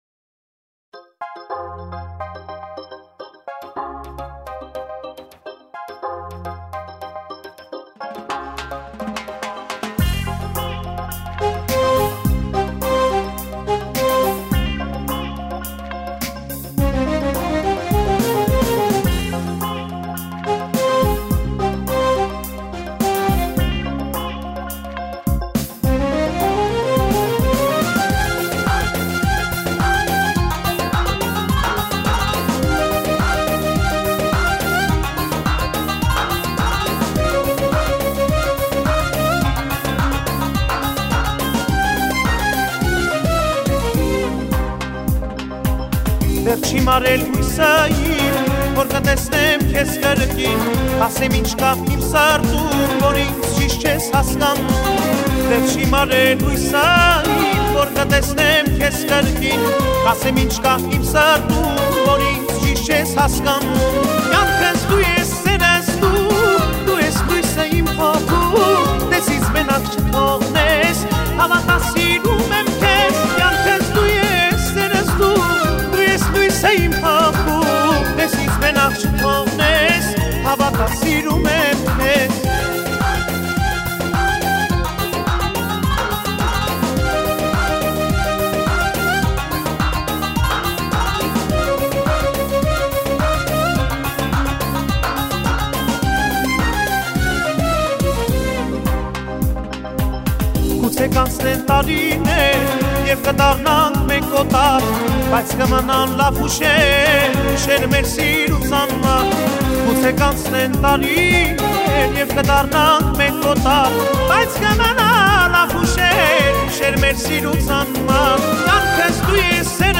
tuyn rabiz erg